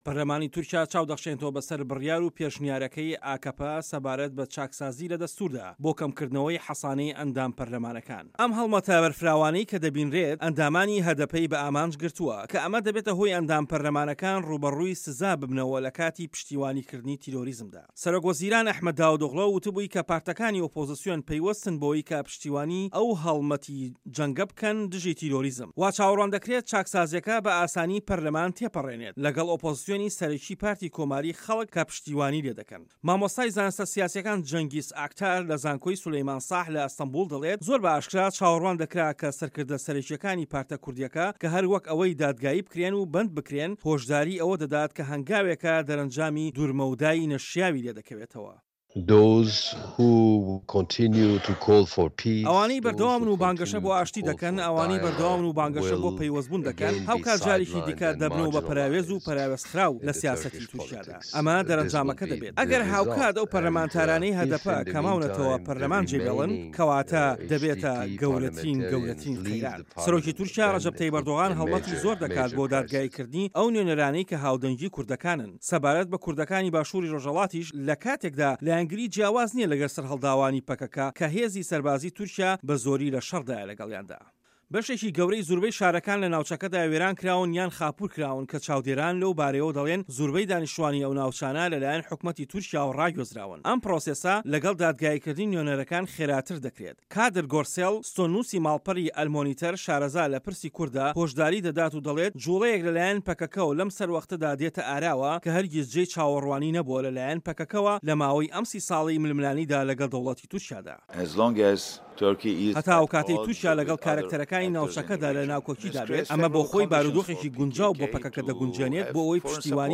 دەقی ڕاپـۆرتەکە